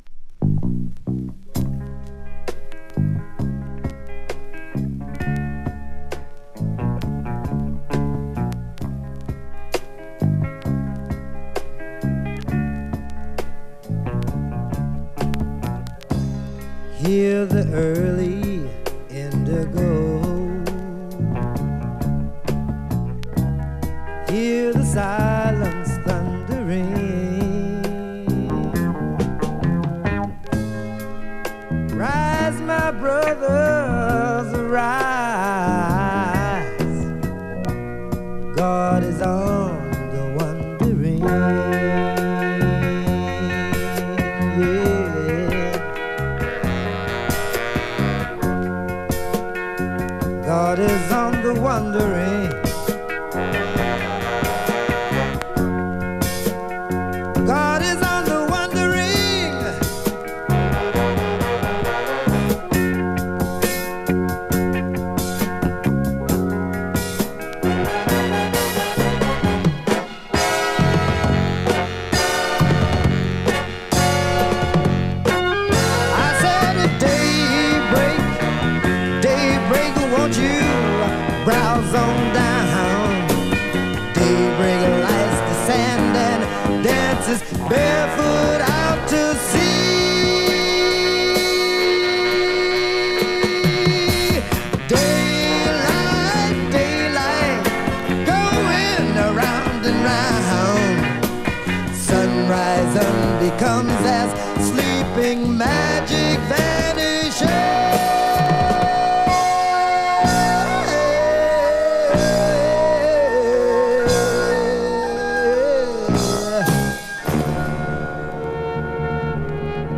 キラー・レア・グルーヴ宝庫盤、1973年作。